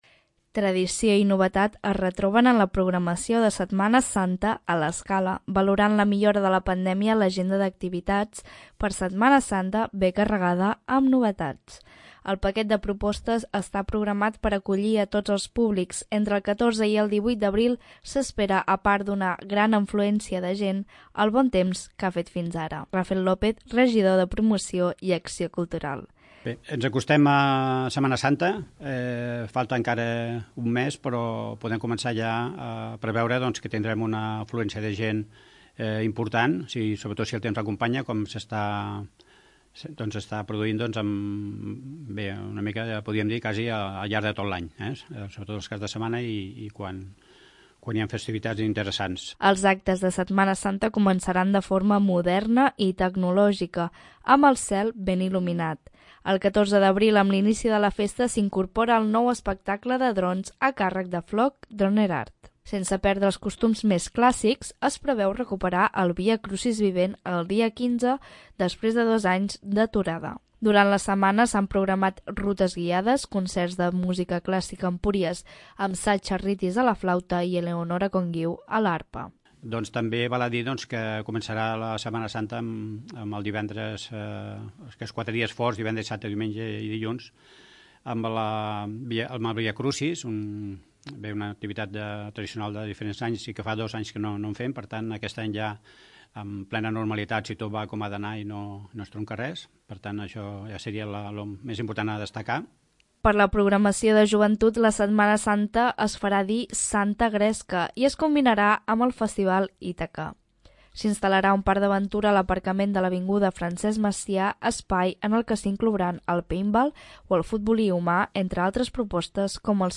{{ tall RAFEL LÓPEZ, Regidor de Promoció i Acció Cultural}}